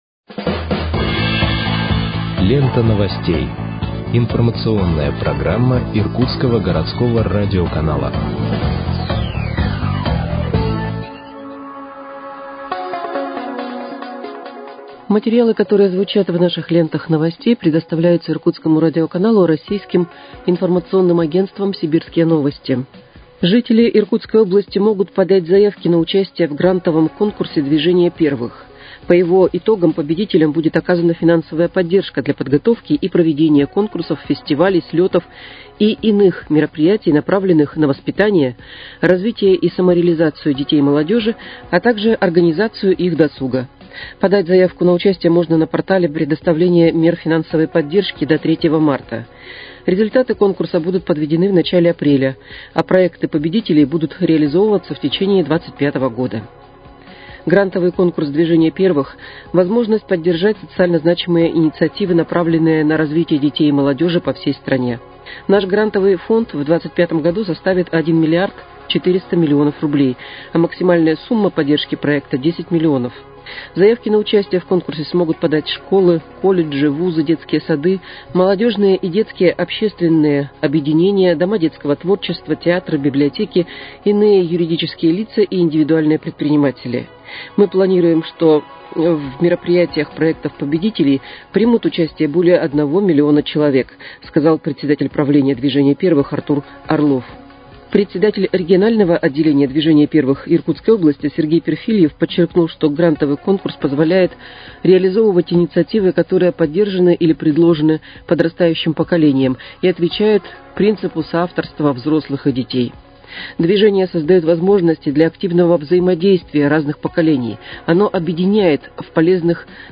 Выпуск новостей в подкастах газеты «Иркутск» от 26.02.2025 № 2